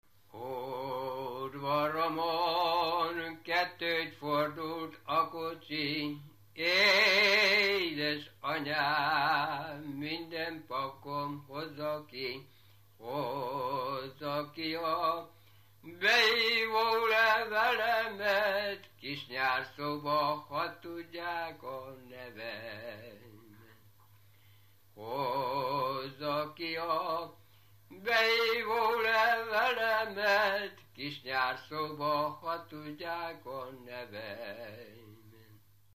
Erdély - Kolozs vm. - Nyárszó
Stílus: 3. Pszalmodizáló stílusú dallamok
Kadencia: 4 (b3) 7 1